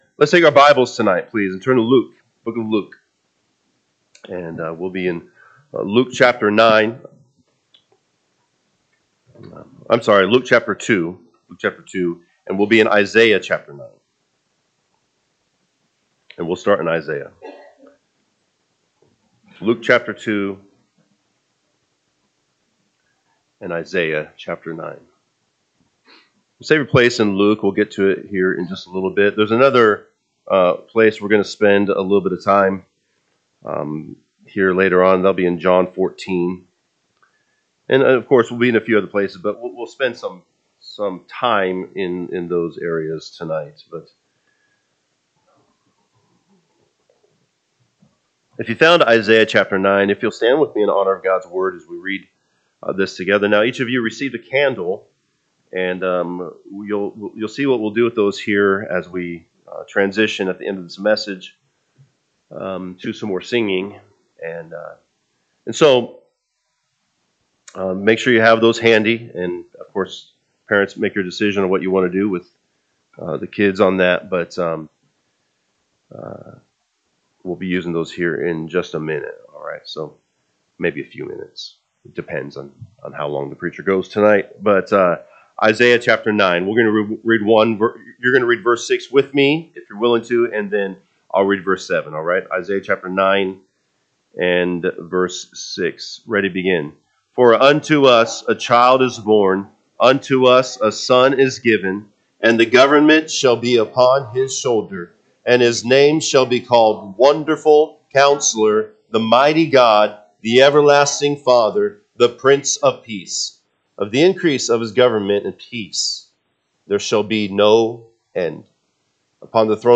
December 24, 2024 Tue. Christmas Eve Service